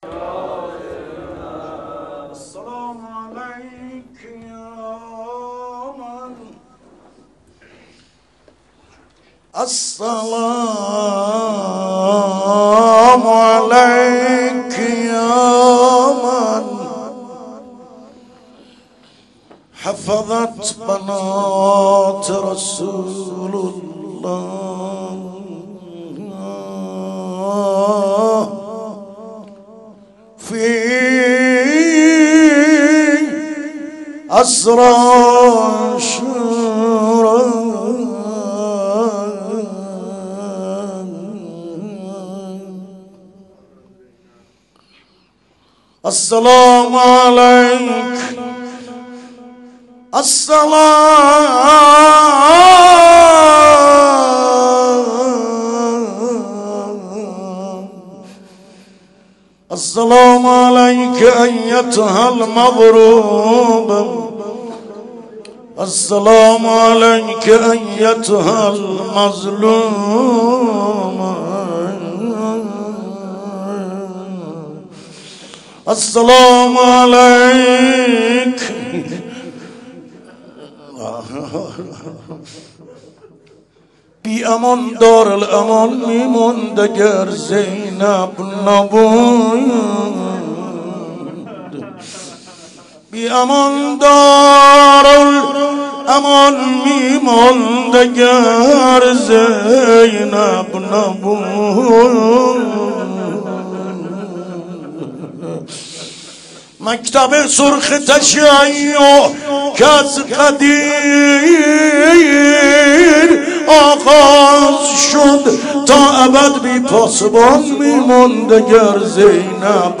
عقیق: مراسم هفتگی عشاق العباس (ع) به مناسبت بزرگداشت حضرت زینب کبری سلام الله عیها برگزار شد.